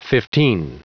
Prononciation du mot fifteen en anglais (fichier audio)
Prononciation du mot : fifteen